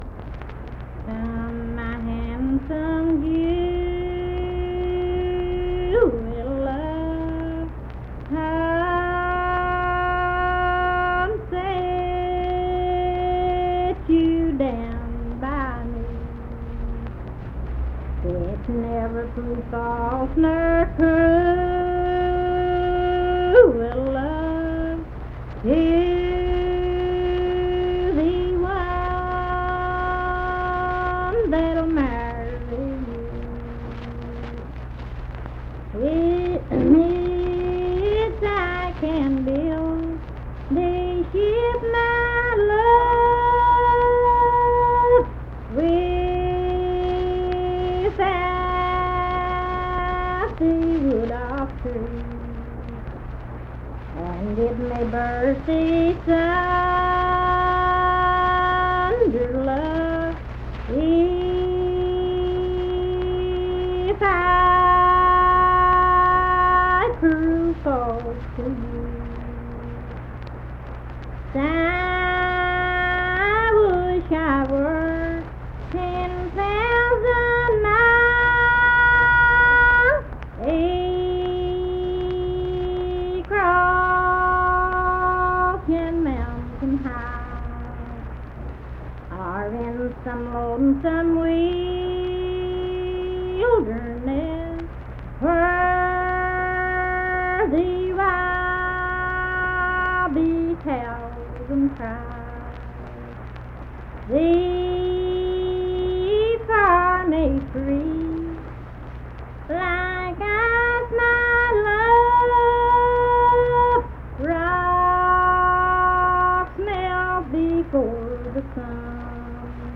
Unaccompanied vocal music
Verse-refrain 7(4).
Voice (sung)
Lincoln County (W. Va.), Harts (W. Va.)